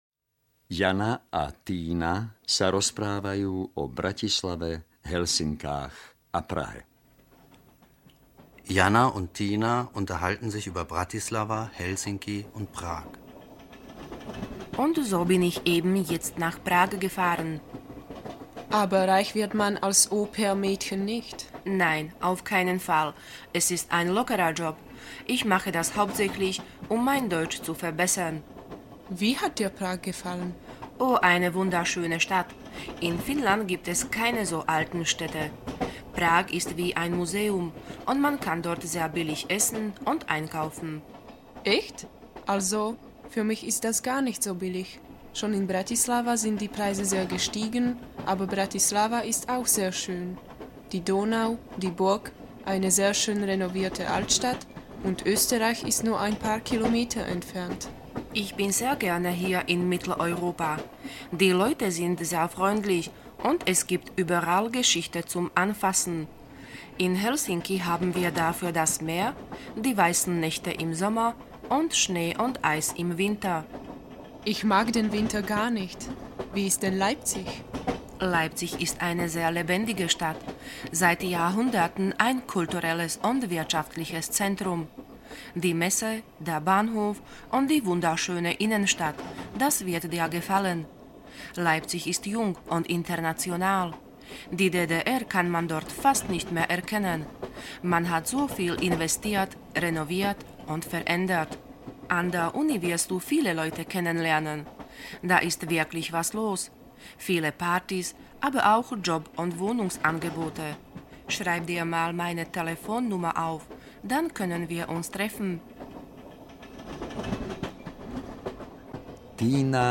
Nemčina pre vašu prácu audiokniha
Ukázka z knihy